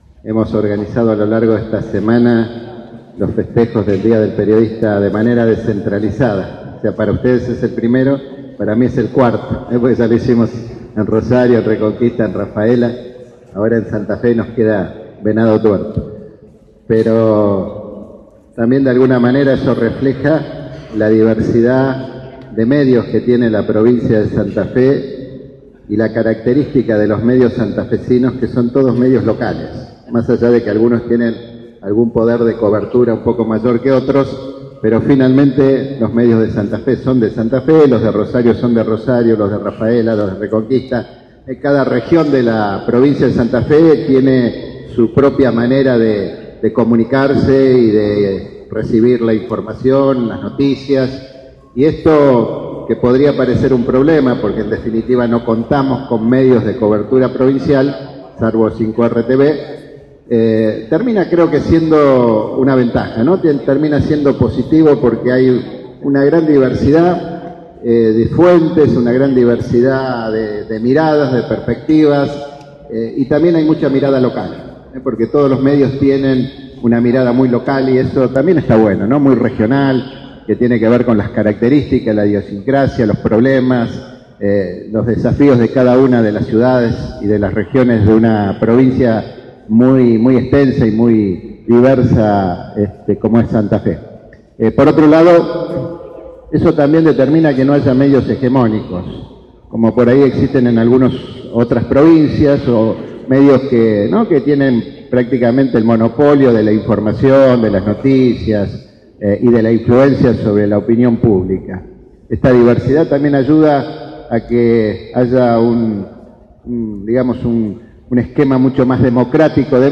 El gobernador Miguel Lifschitz participó este jueves del brindis de agasajo a los trabajadores de prensa de la ciudad de Santa Fe y la región, en el marco de la celebración del Día del Periodista que se conmemora este viernes 7 de junio.
Miguel Lifschitz